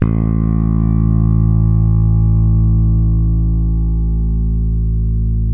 -JP ROCK A 2.wav